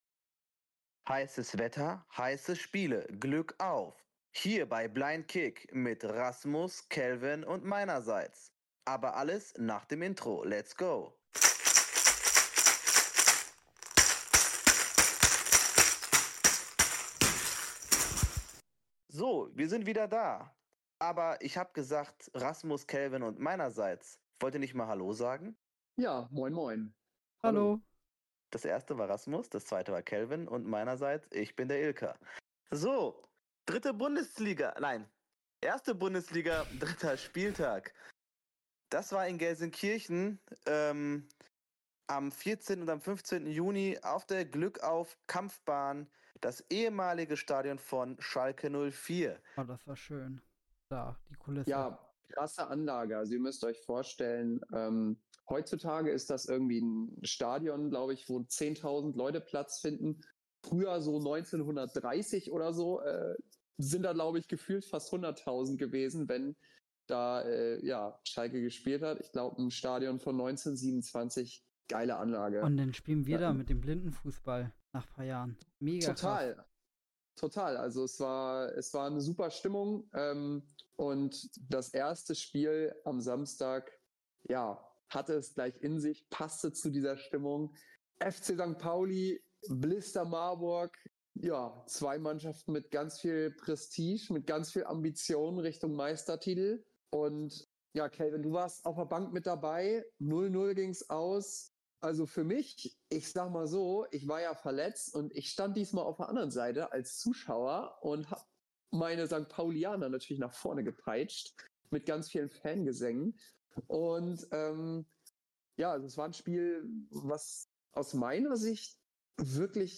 In der Podcastfolge sprechen die Moderatoren von Blind Kick über den dritten Blindenfußball-Bundesliga-Spieltag. Es gab viel Sonne und spannende Spiele.